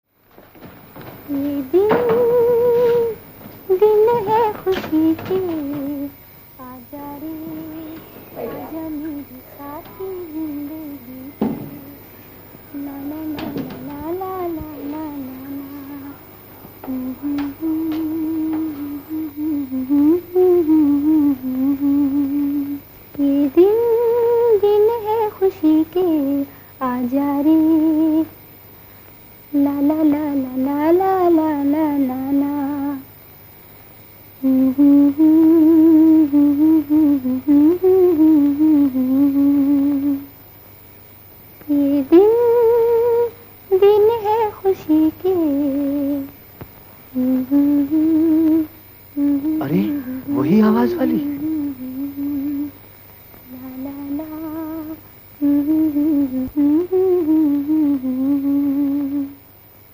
humming